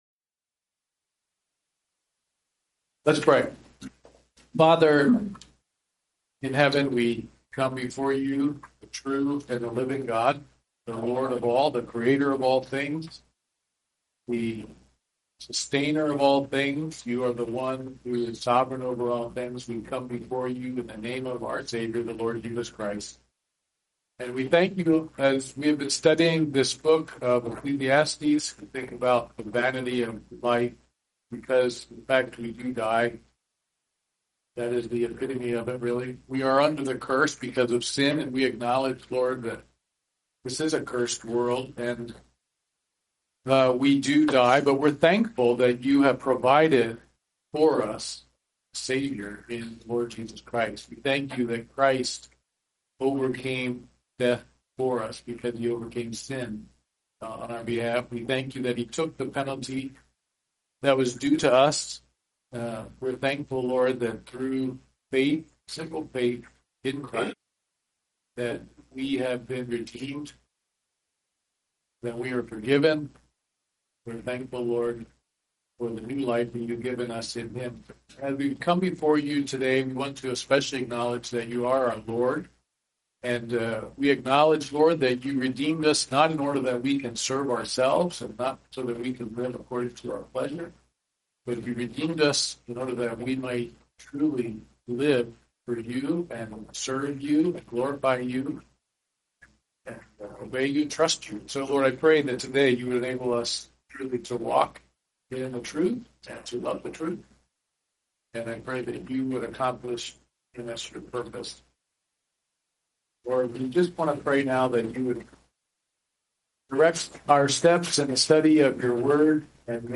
Ecclesiastes 7:27-29 Service Type: Wednesday Morning Bible Study « The Certainty of Christs Second Coming 2 The People Tested and the LORD Provides